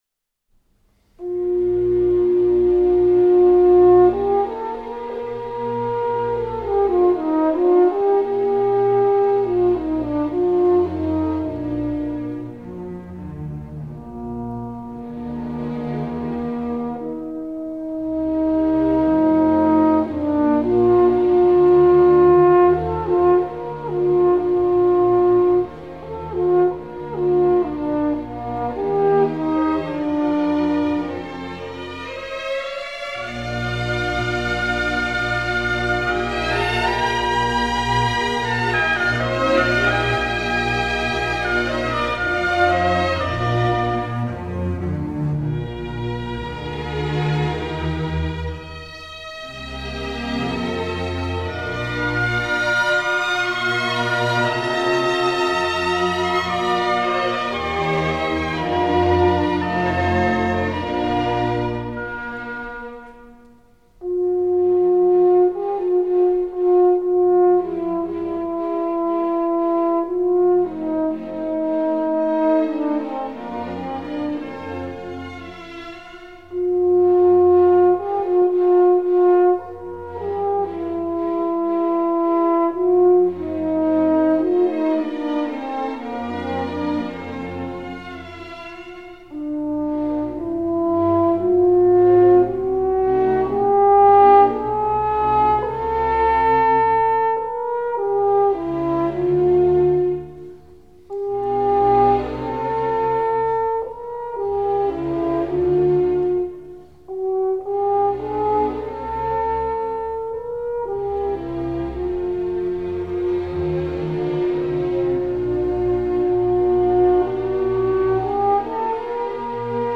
F04-10 Horn Concerto No. 4 in E flat maj | Miles Christi
F04-10-Horn-Concerto-No.-4-in-E-flat-maj.mp3